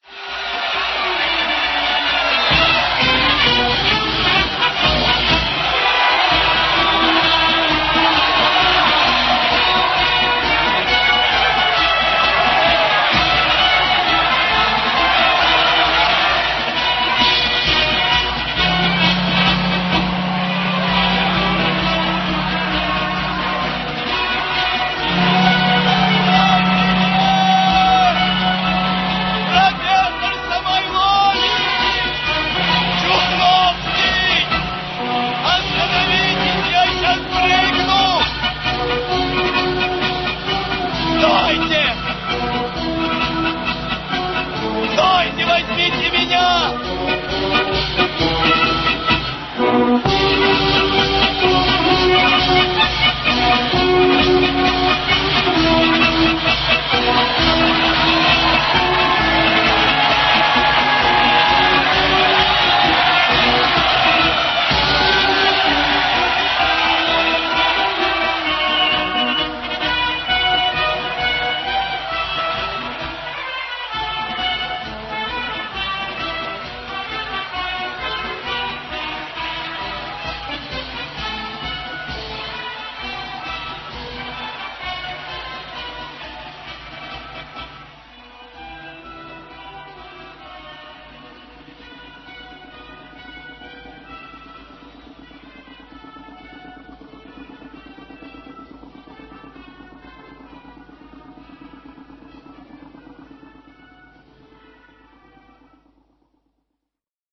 Полная драматизма, сцена эта идёт в фильме под звуки «Прощания славянки».